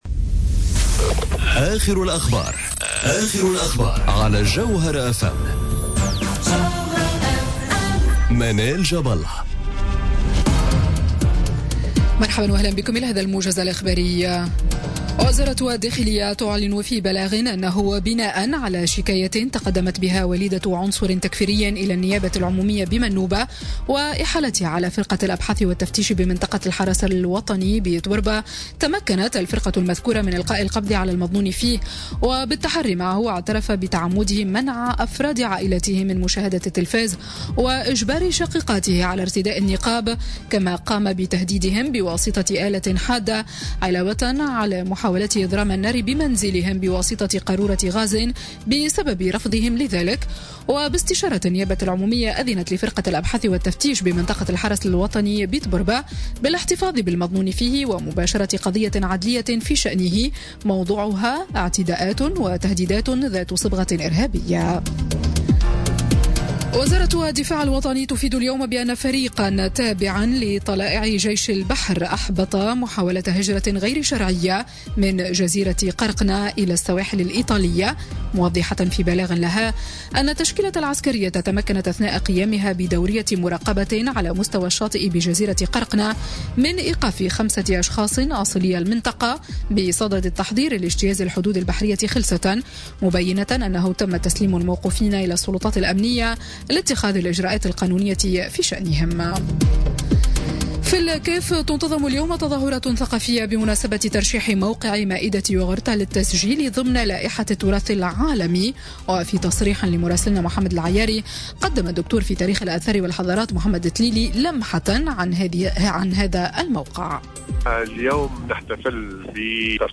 نشرة أخبار منتصف النهار ليوم السبت 9 سبتمبر 2017